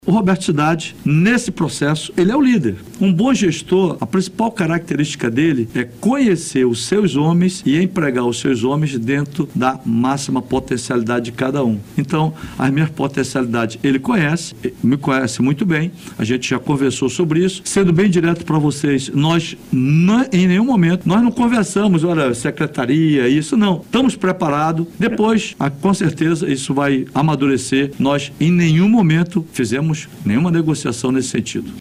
Em entrevista no BandNews Amazônia 1ª Edição nesta terça-feira, 06, Menezes negou que tenha provocado o ex-presidente.